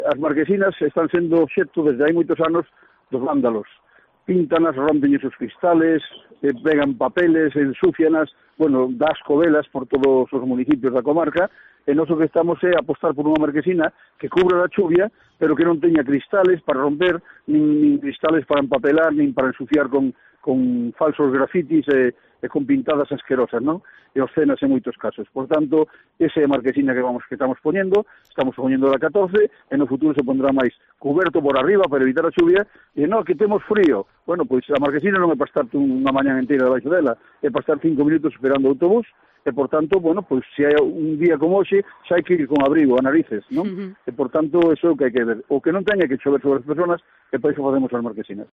Angel García Seoane, alcalde de Oleiros